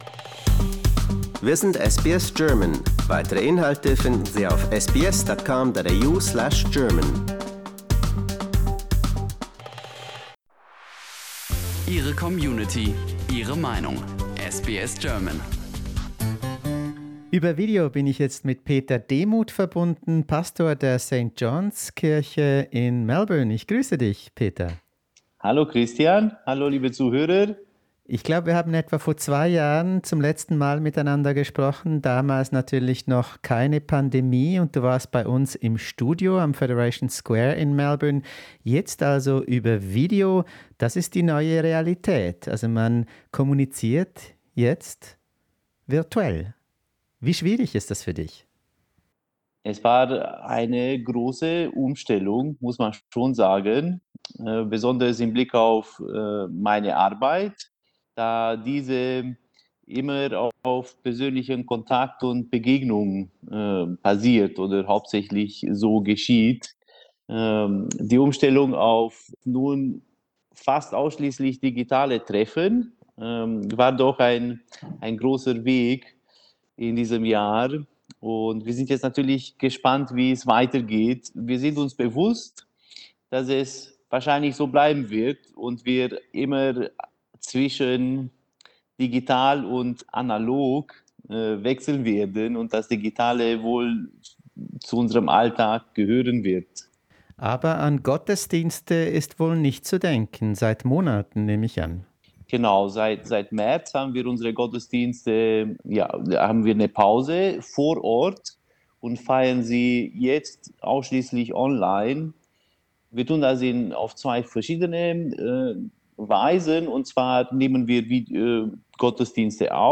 Was denkt er über den Corona-Lockdown? Wie geht er in seiner Kirche und als Privatmensch mit der Pandemie um? Mehr dazu im Interview mit dem aus Siebenbürgen in Rumänien stammenden Seelsorger.